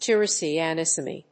tyrosinemia.mp3